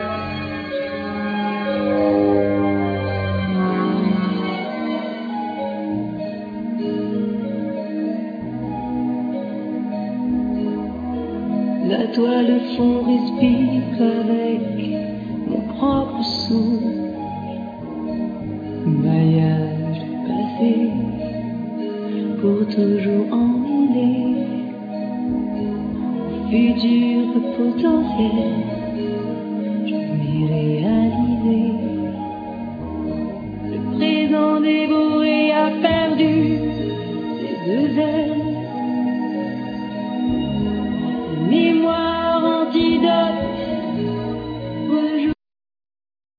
Guitar,Keyboard,Sequencer
Violin,Viola
Drums,Percussion
Clarinett
Double Bass
Cello
Flute